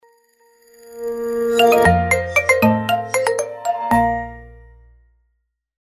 Звуки уведомлений WhatsApp